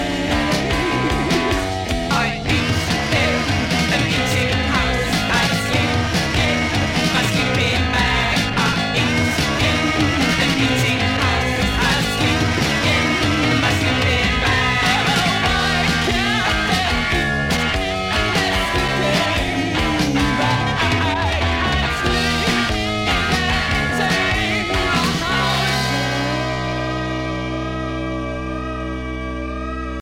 saxofone
Som claramente - diríamos até orgulhosamente - datado